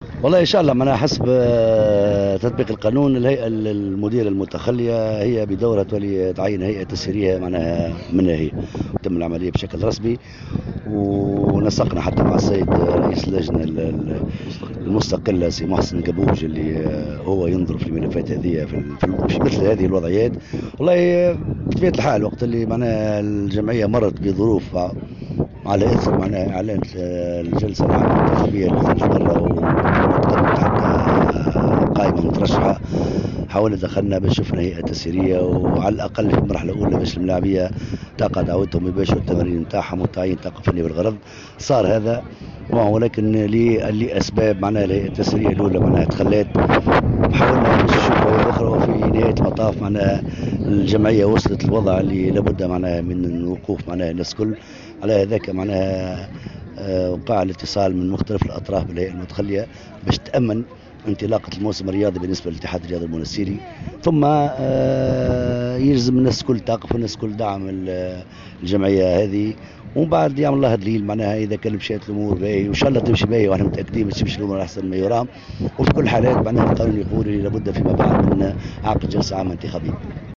أكد رئيس بلدية المنستير منذر مرزوق في تصريح لمراسل جوهرة اف ام أنه وفقا للقانون الأساسي لجمعية الإتحاد المنستيري ، يتعين على الهيئة المديرة المتخلية تعيين هيئة تسييرية وقتية لتسيير شؤون النادي إلى حين تحديد موعد للجلسة العامة الإنتخابية.